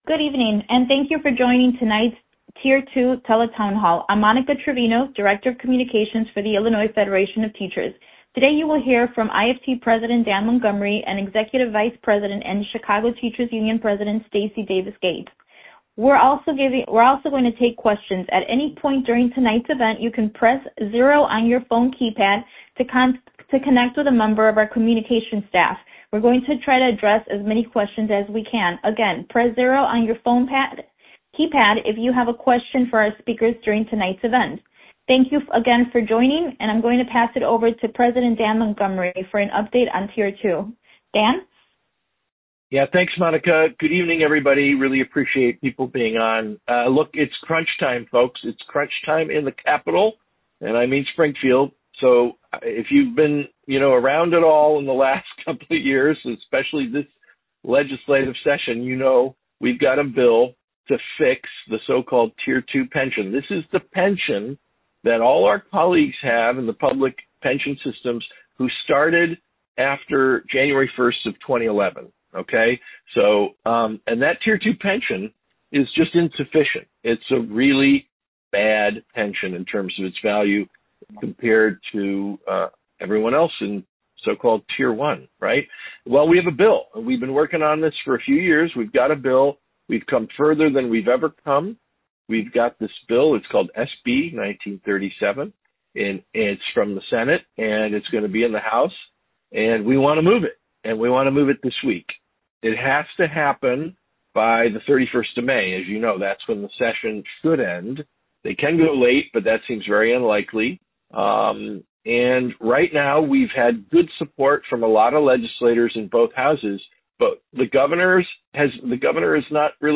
held a telephone town hall to update members around the state on the status of our fight to fix Tier 2 pensions.